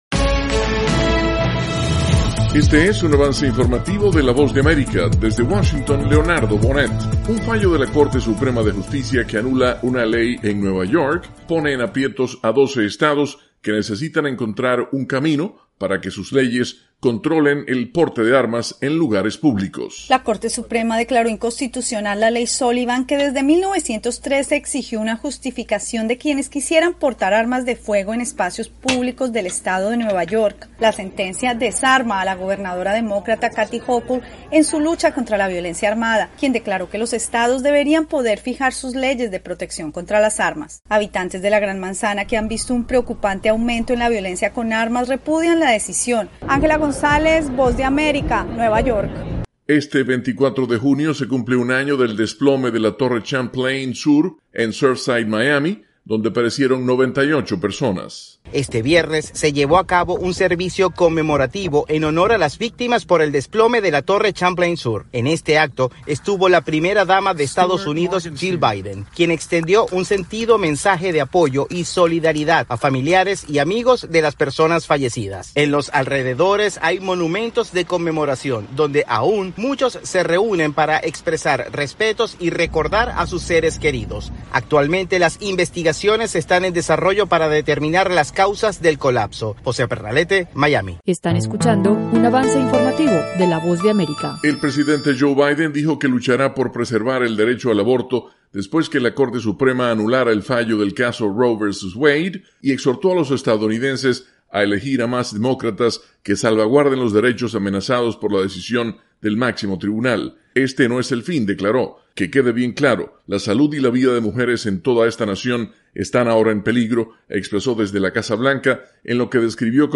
Avance Informativo - 7:00 PM